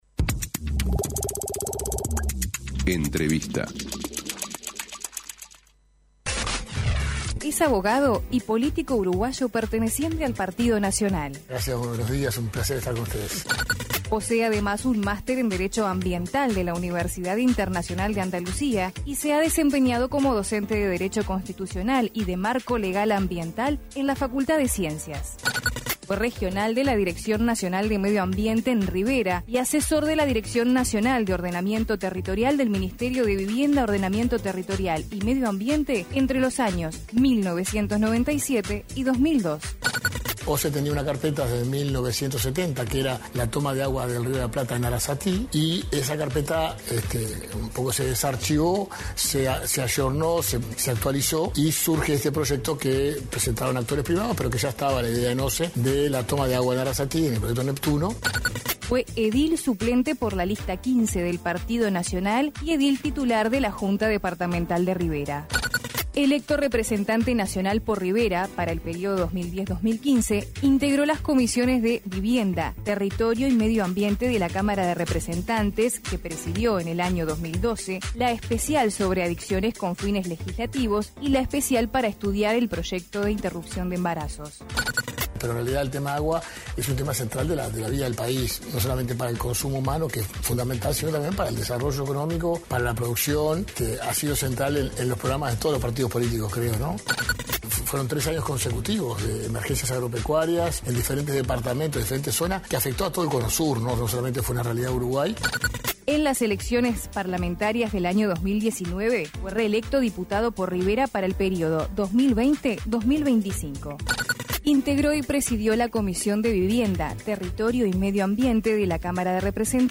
Entrevista a Gerardo Amarilla